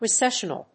/rɪséʃ(ə)nəl(米国英語)/